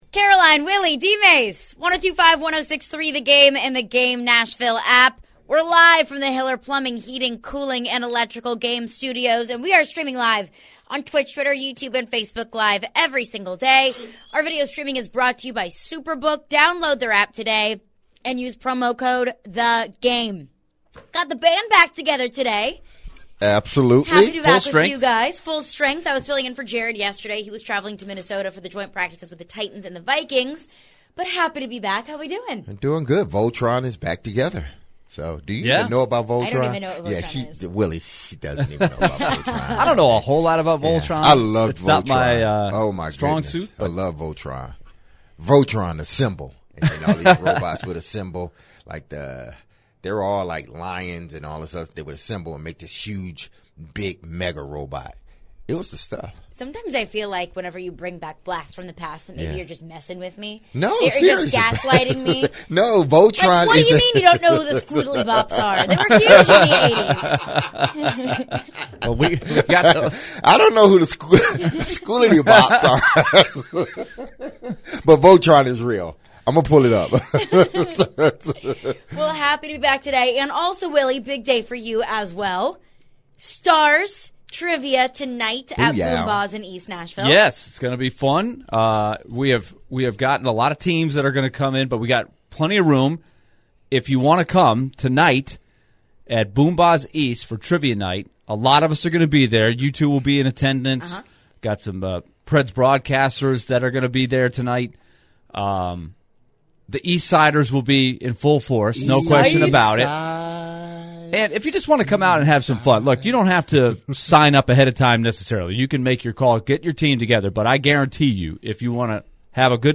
talk Titans joint practices with the Minnesota Vikings before Mike Vrabel takes the podium and we tune in so you can hear the head coach's thoughts.